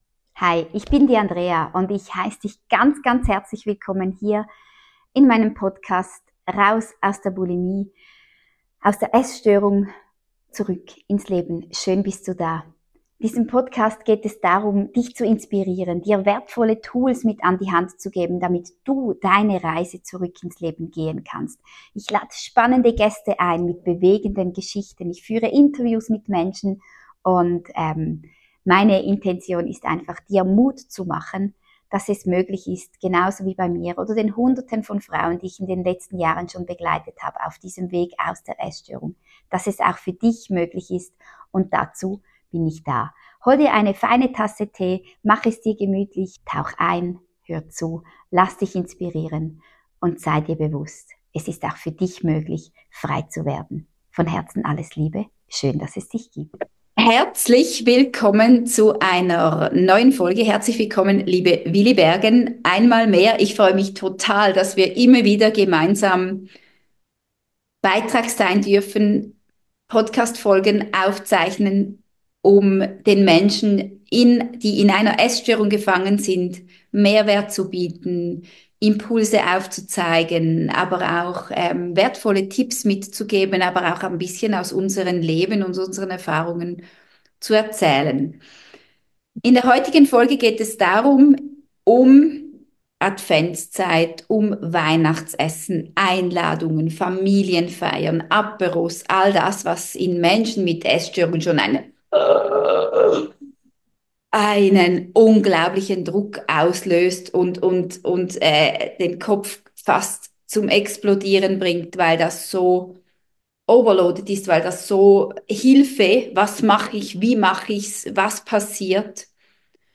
Essstörung in der Weihnachtszeit - Interview